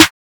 Snare (Shiiit).wav